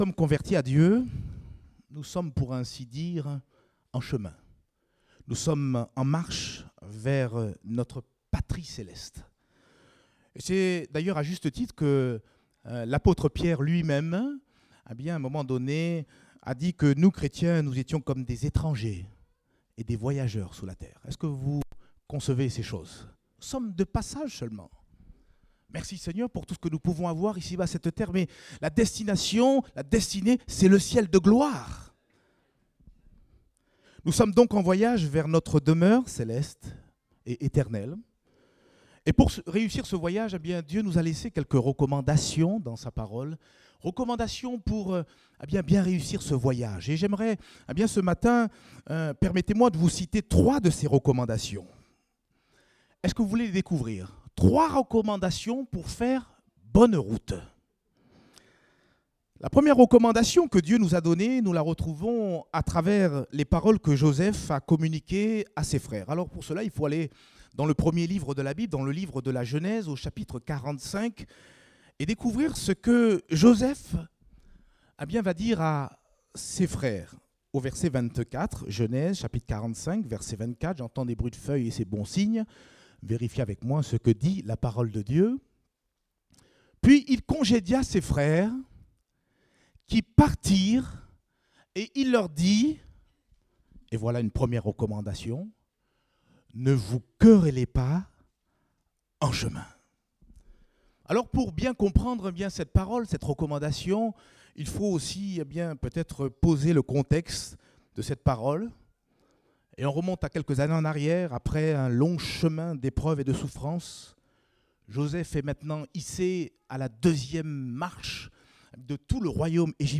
Date : 13 août 2017 (Culte Dominical)